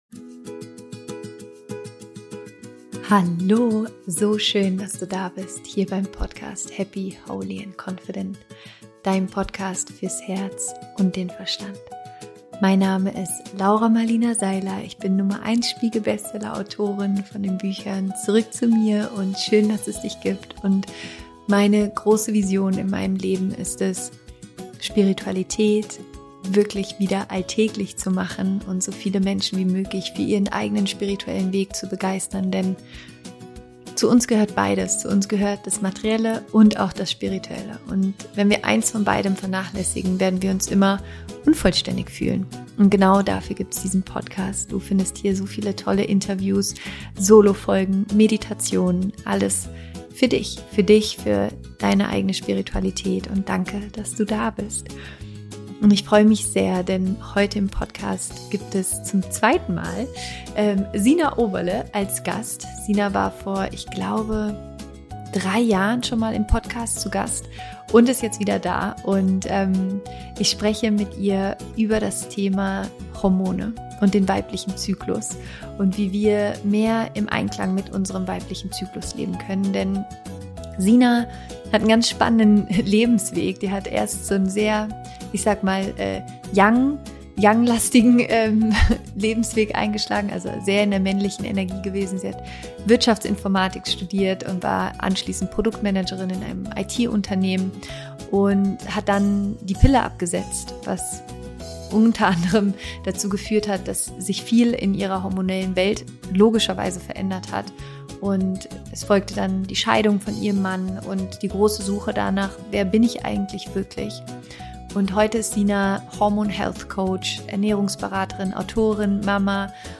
Viel Spaß mit diesem so spannenden Interview!